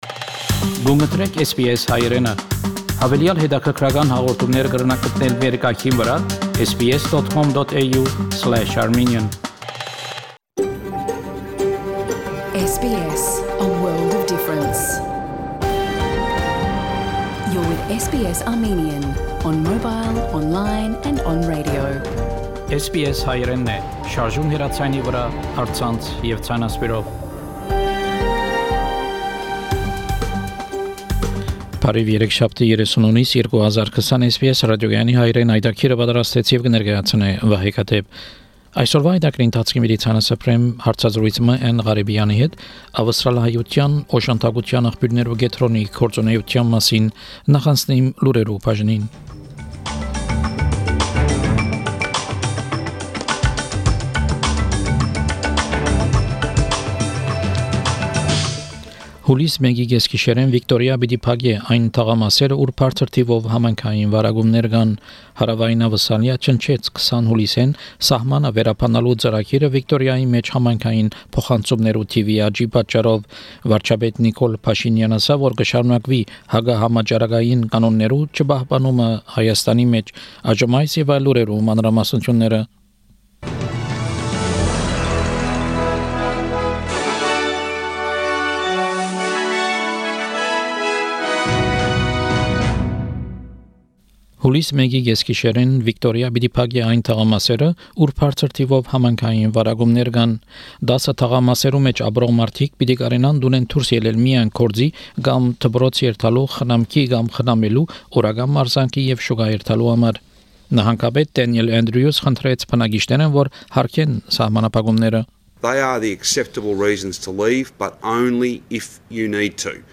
SBS Armenian news bulletin – 30 June 2020